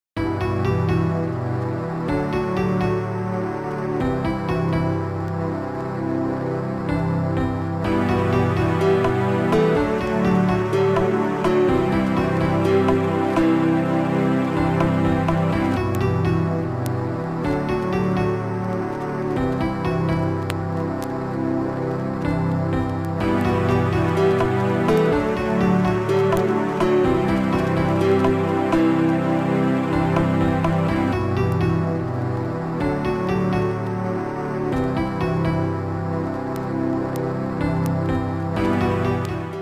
auf einem Amiga 500 & Amiga 4000.